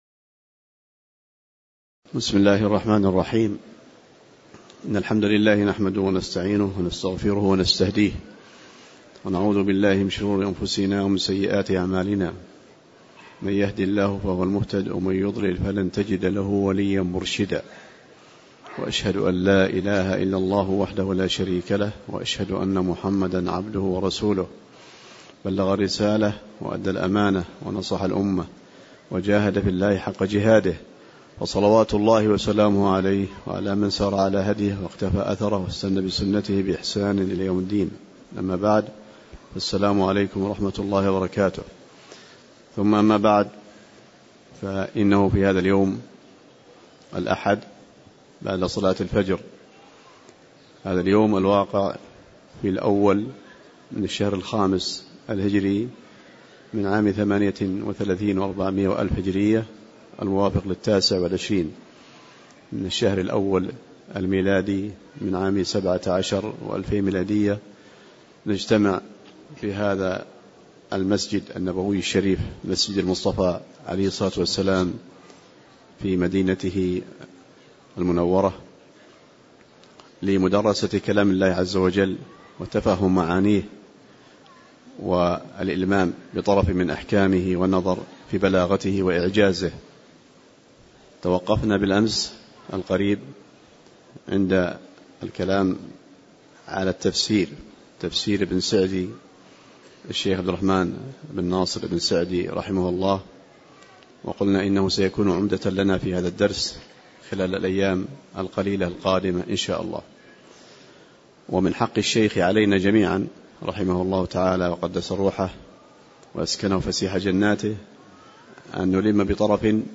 تاريخ النشر ١ جمادى الأولى ١٤٣٨ هـ المكان: المسجد النبوي الشيخ